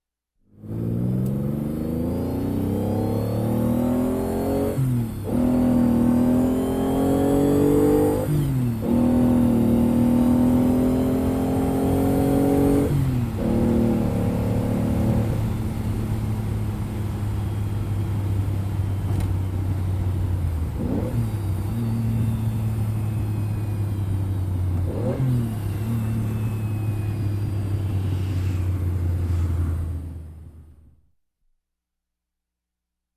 Звуки гоночного автомобиля
Звук из салона гоночного автомобиля при движении